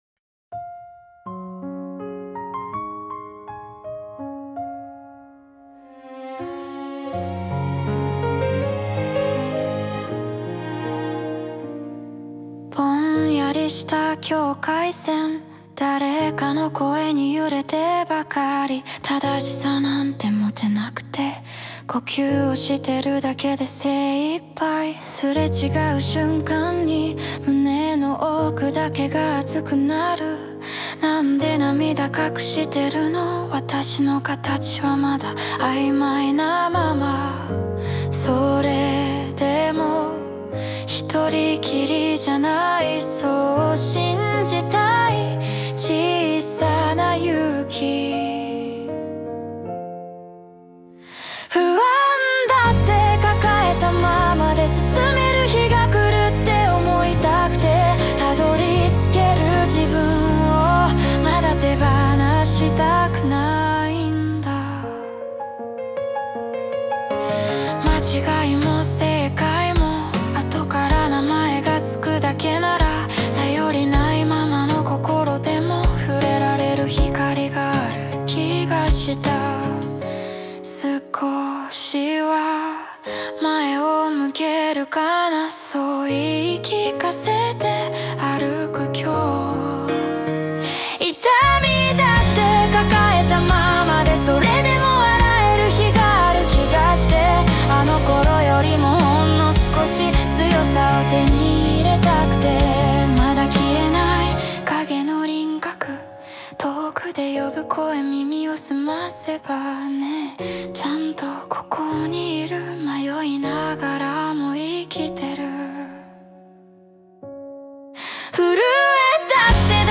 女性ボーカル邦楽邦楽 女性ボーカル作業BGMポップスバラード切ない幻想的
著作権フリーオリジナルBGMです。
女性ボーカル（邦楽・日本語）曲です。